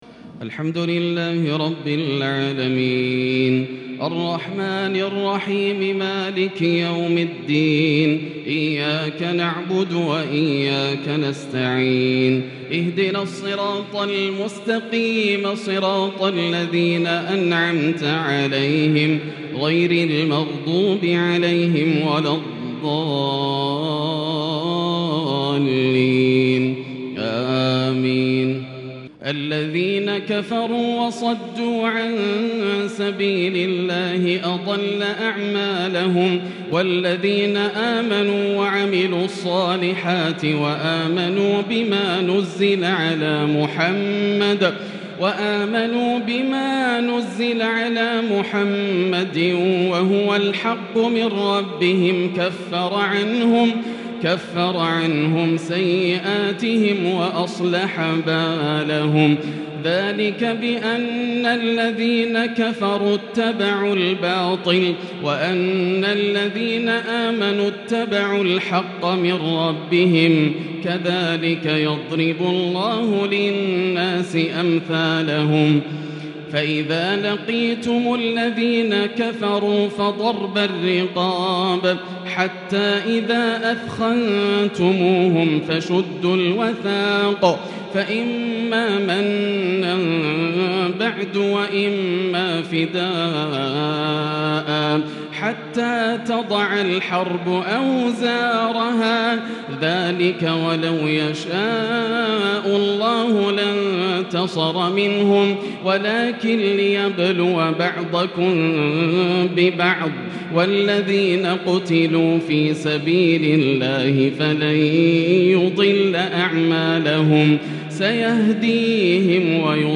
صلاة التراويح | ليلة 27 رمضان 1442 l سورة ( محمد - الفتح 17 ) | taraweeh prayer The 27th night of Ramadan 1442H | from surah Mohammed and Al-Fath > تراويح الحرم المكي عام 1442 🕋 > التراويح - تلاوات الحرمين